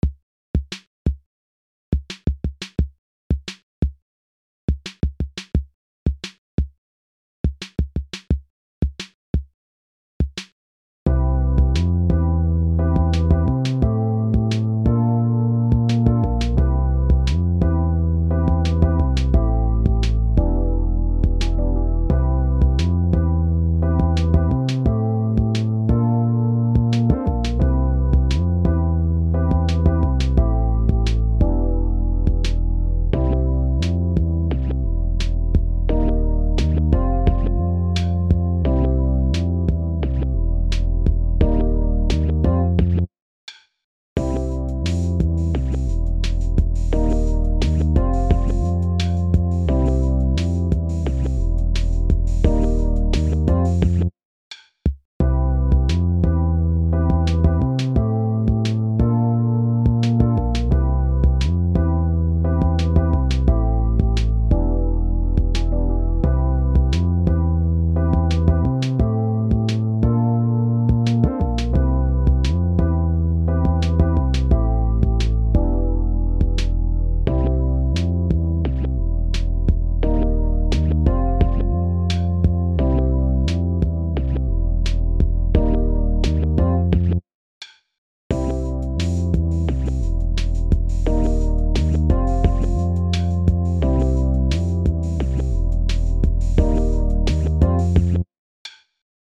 This one’s funny!